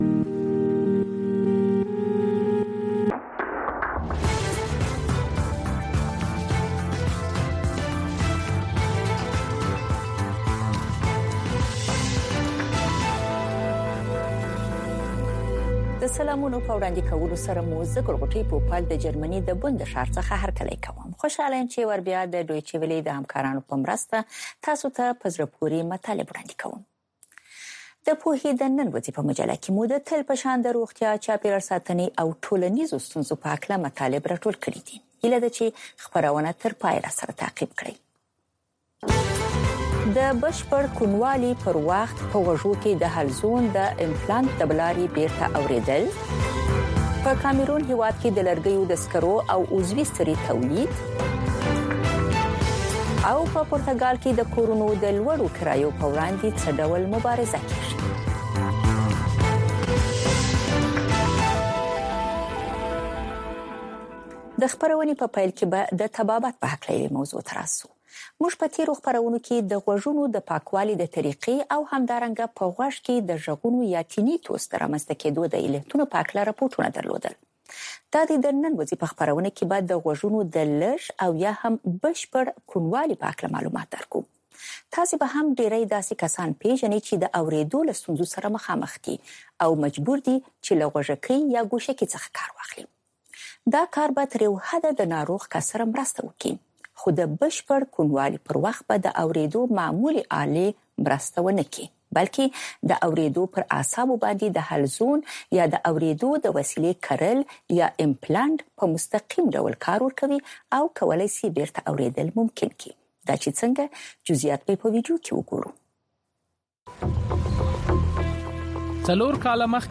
د وی او اې ډيوه راډيو خبرونه چالان کړئ اؤ د ورځې د مهمو تازه خبرونو سرليکونه واورئ.